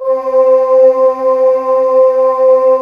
Index of /90_sSampleCDs/USB Soundscan vol.28 - Choir Acoustic & Synth [AKAI] 1CD/Partition C/09-GREGOIRE